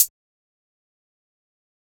Closed Hats
Crystal Hat.wav